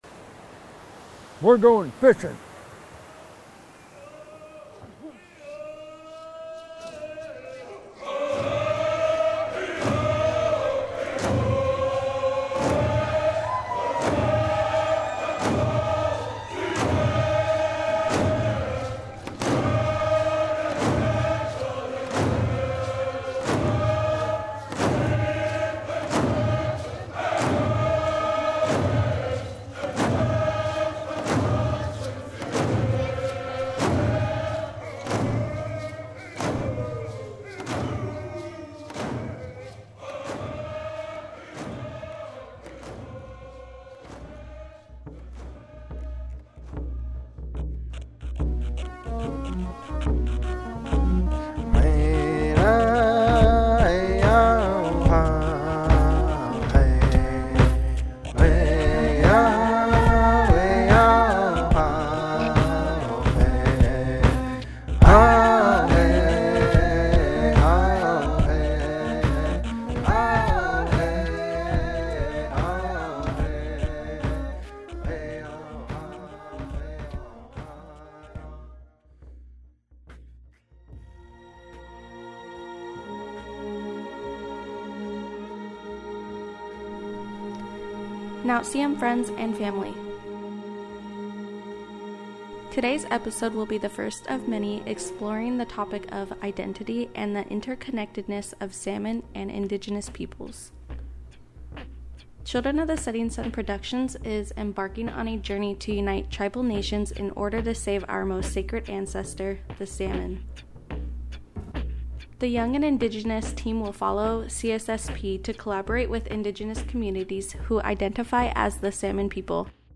You will also hear voices of Indigenous Leaders from the first Salmon People Project gathering held via zoom in March 2021. This Podcast shares salmon values, personal connections held with salmon, how important the salmon is to the environment and Native culture, and concludes with a call to action.